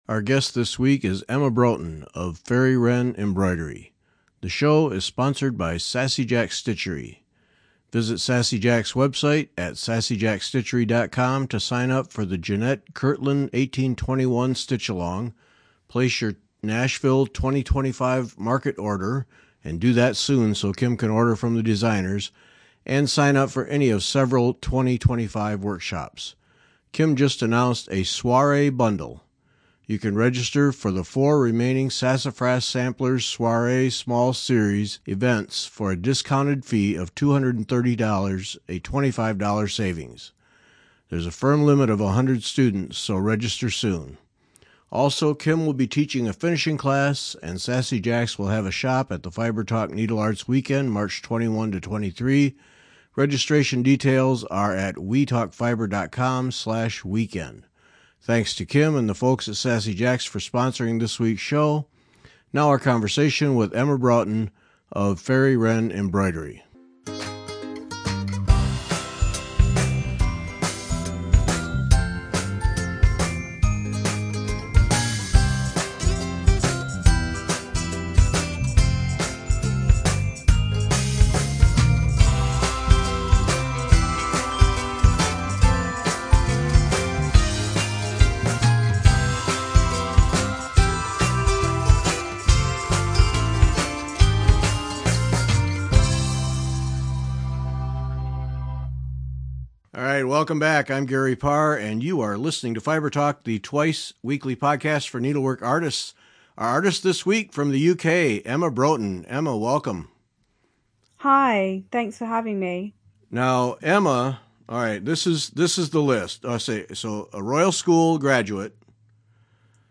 Enjoy the conversation and visit her website to learn more.